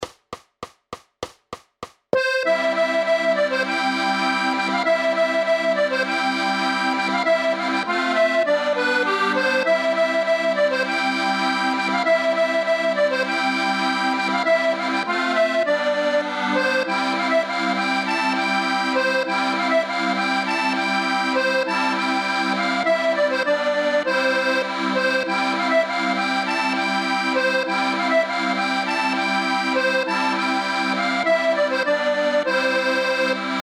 Noty na akordeon.
Hudební žánr Klasický